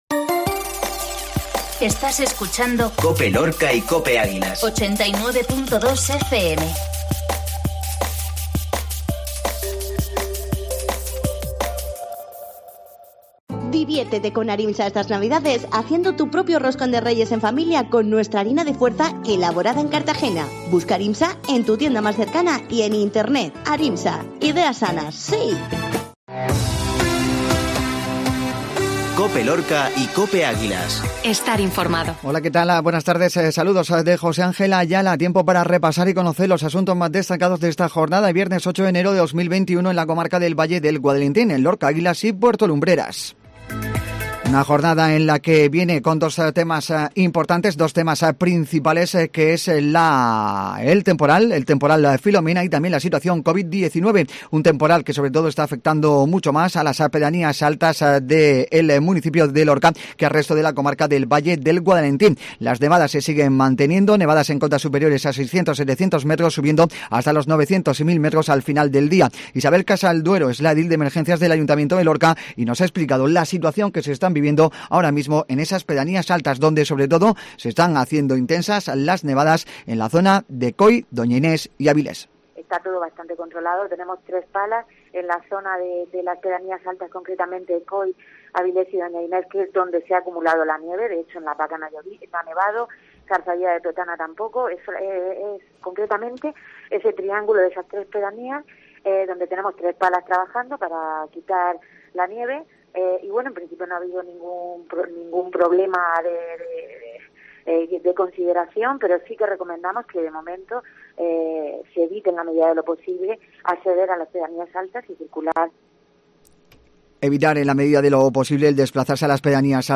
INFORMATIVO MEDIODIA COPE LORCA